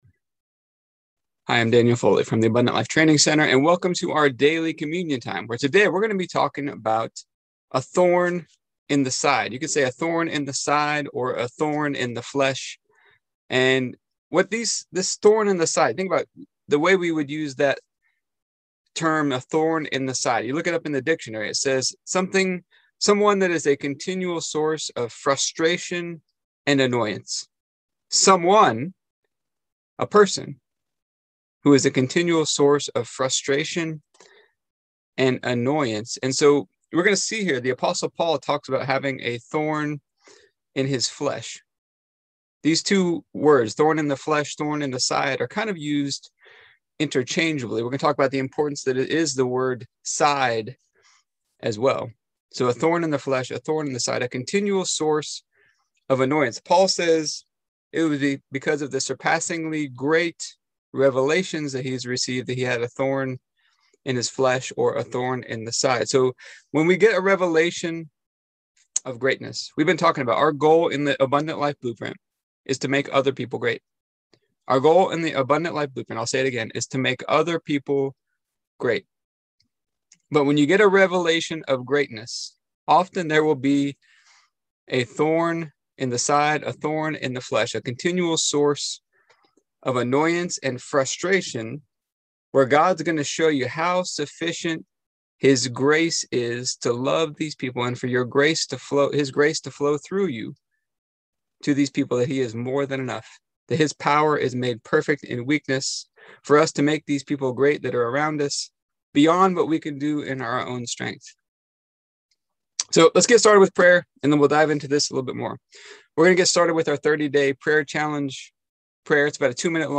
Here is today's communion meditation.